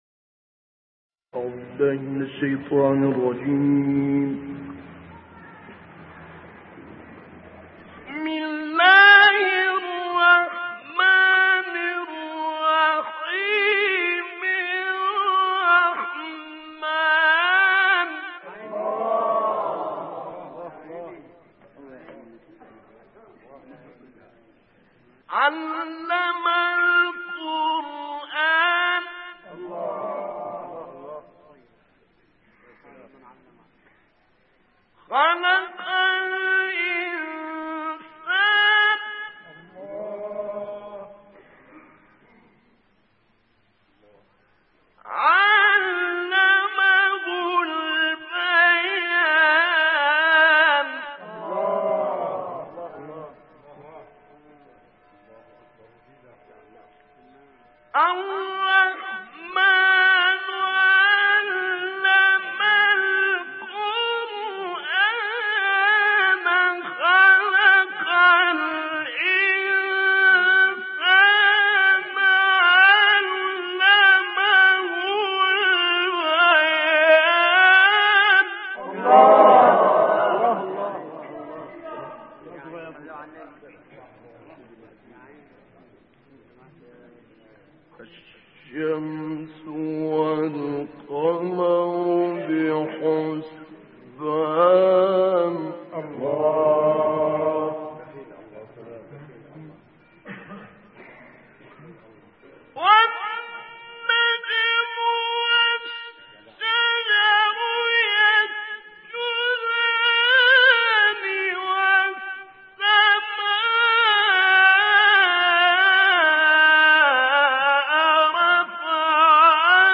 تلاوت کوتاه مجلسی ابوالعینین شعیشع از آیه 1 تا 9 سوره الرحمن به مدت 3 دقیقه و 42 ثانیه